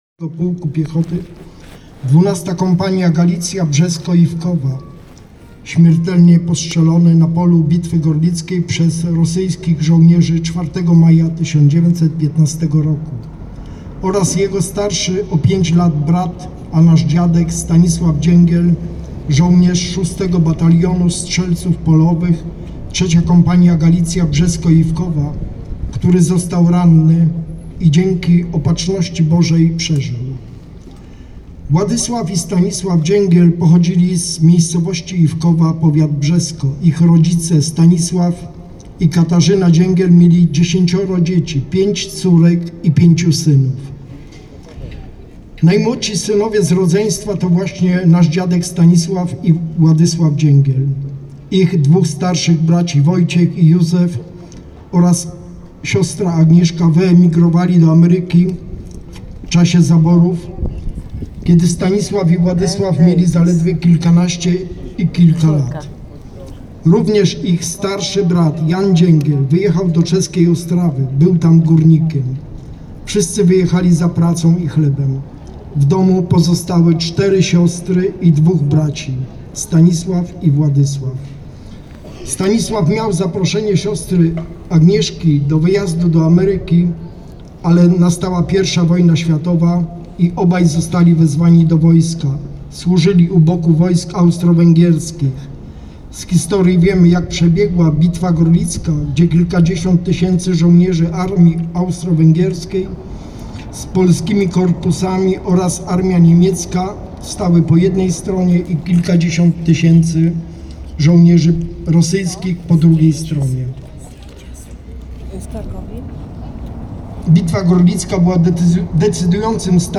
podczas uroczystości w Bieczu w dniu 3 maja 2025 roku